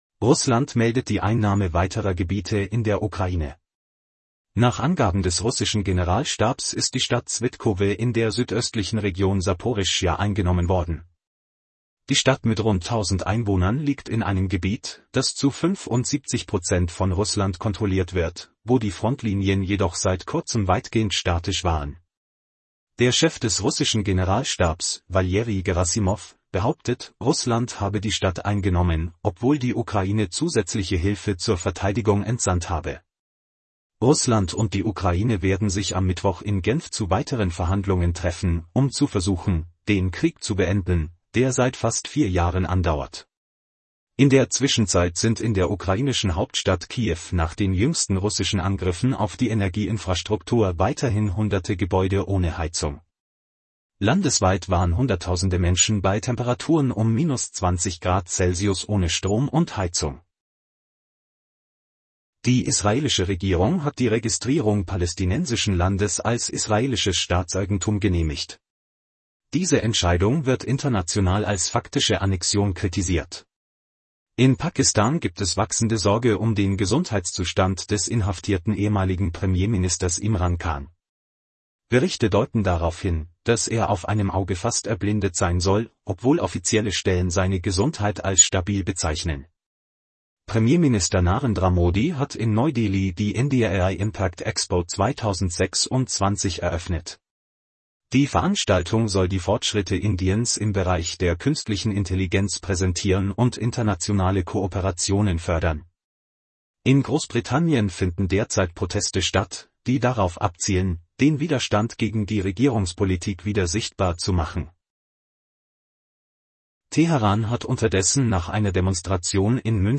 Dies ist ein Nachrichten-Podcast aus Dutzenden von Kurzberichten, zum hands-free Hören beim Autofahren oder in anderen Situationen.